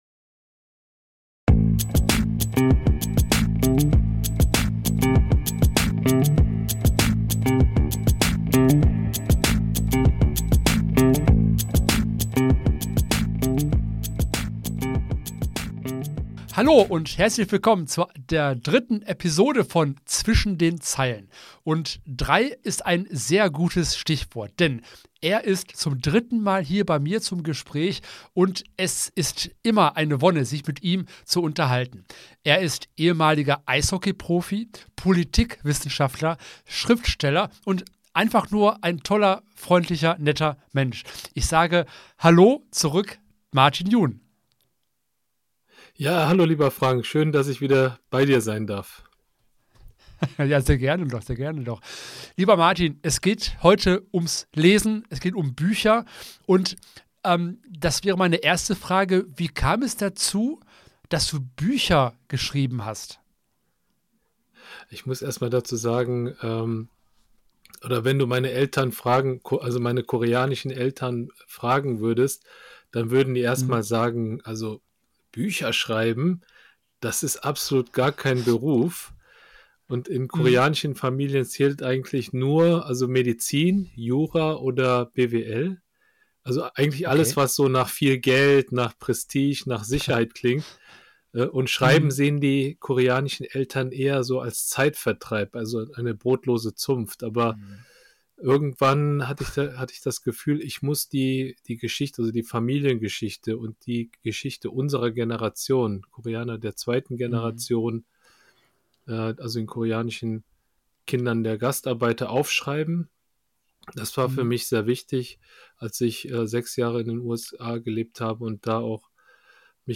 Endlich wieder Sonntag und endlich wieder ein Gespräch übers Lesen und Schreiben.
Dazu hat er uns drei Kapitel eingelesen, die es dann am Ende gibt.